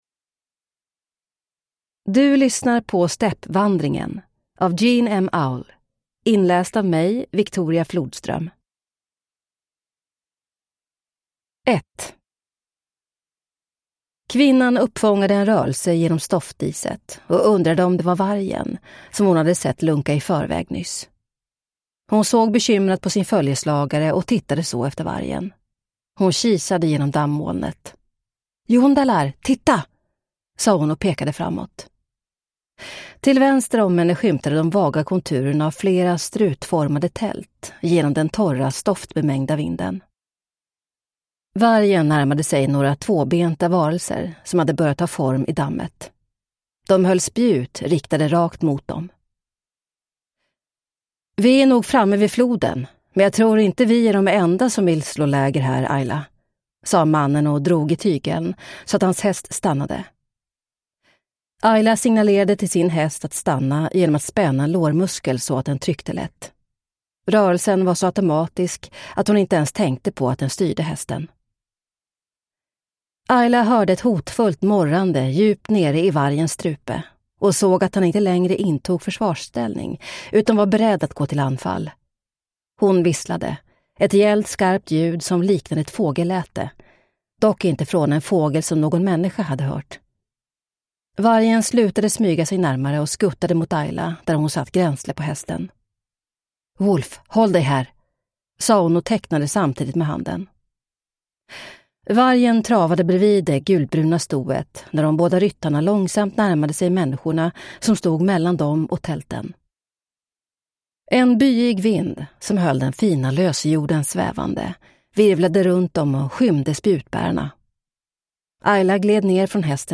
Stäppvandringen – Ljudbok – Laddas ner